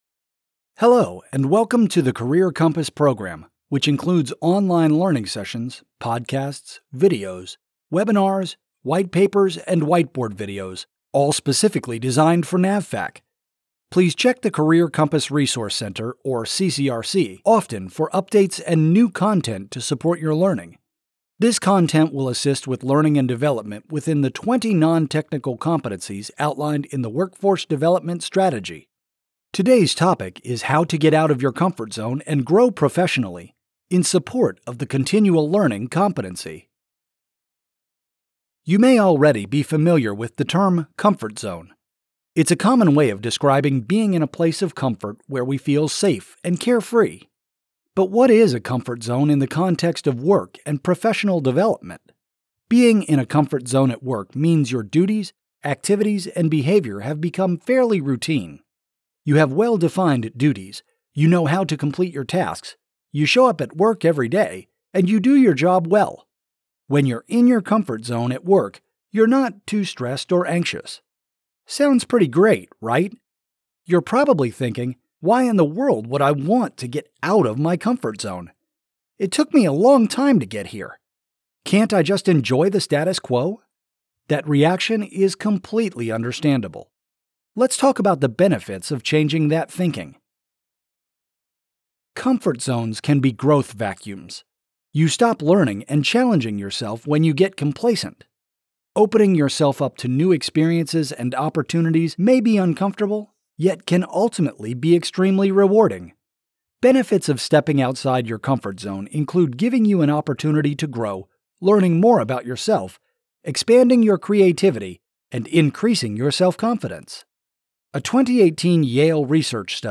These 5 – 10 minute podcasts include facilitated discussions on select competency-related topics. They contain tips and techniques listeners can learn and quickly apply on-the-job.